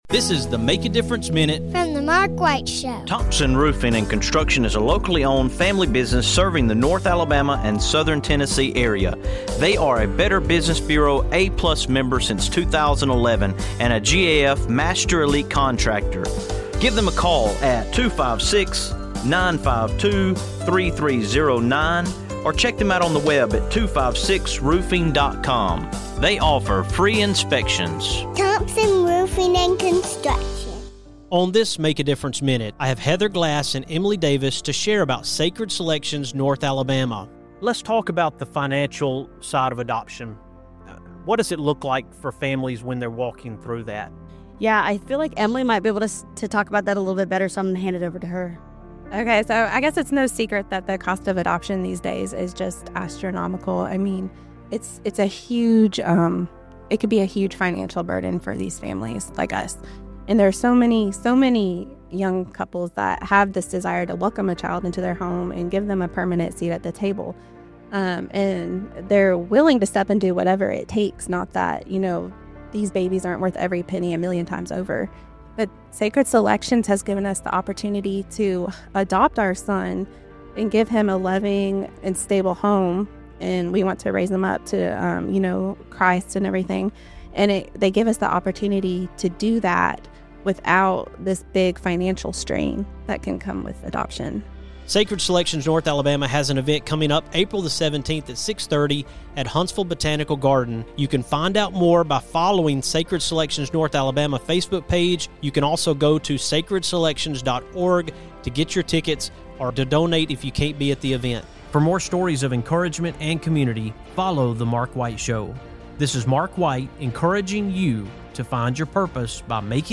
If you’ve ever wondered how you can be part of something bigger than yourself, this is a conversation worth hearing.